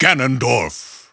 The announcer saying Ganondorf's name in English and Japanese releases of Super Smash Bros. Brawl.
Ganondorf_English_Announcer_SSBB.wav